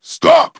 synthetic-wakewords
ovos-tts-plugin-deepponies_Kratos_en.wav